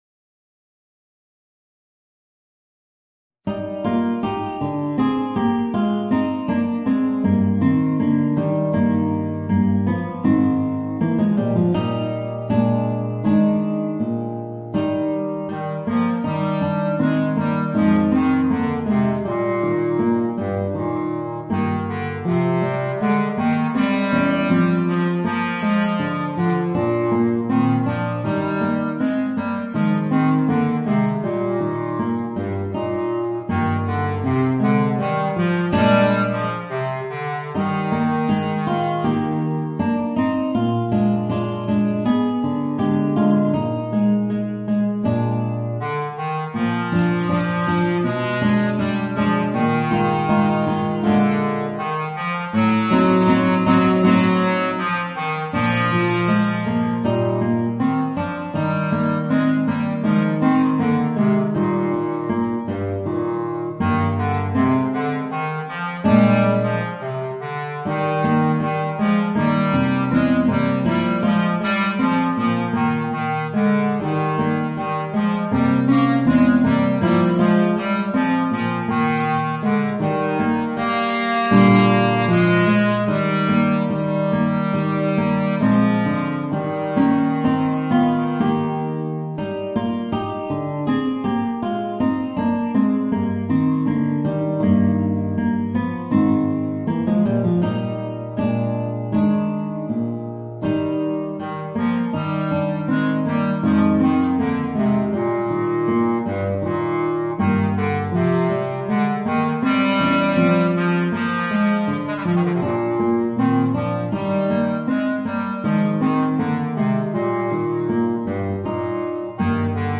Voicing: Guitar and Bass Clarinet